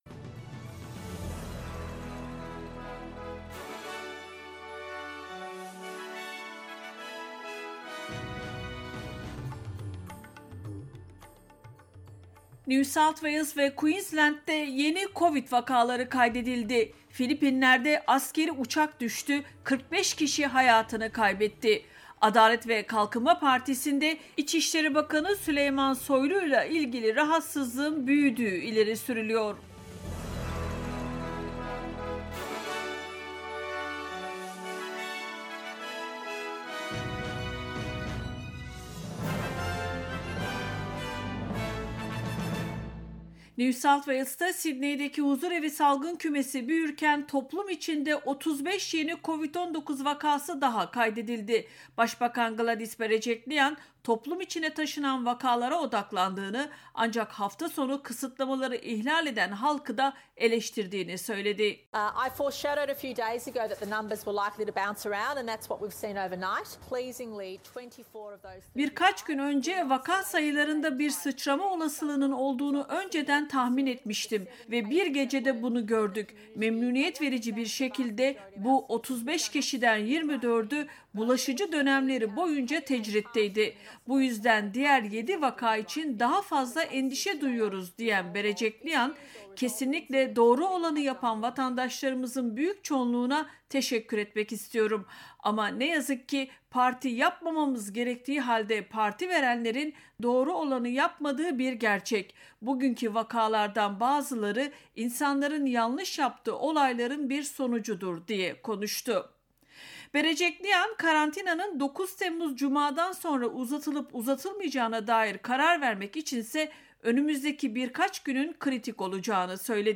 SBS Türkçe’den Avustralya, Türkiye ve dünyadan haberler.